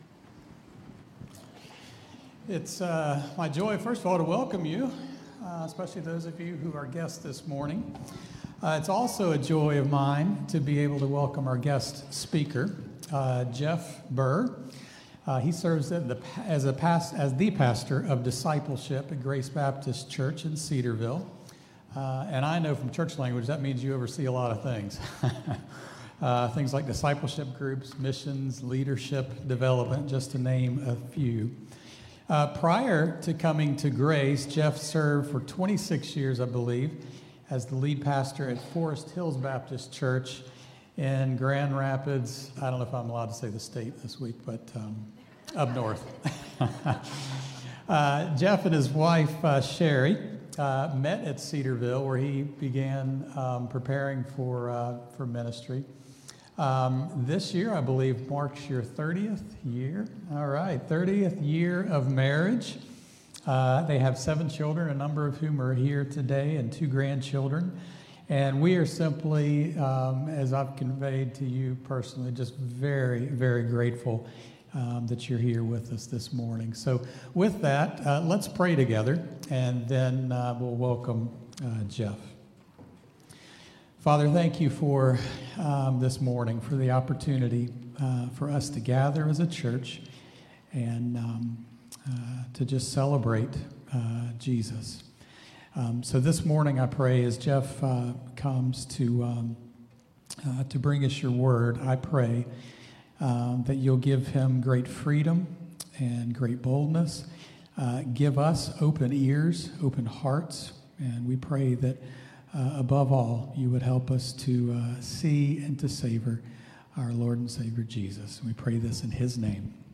Highland Baptist Church Sermons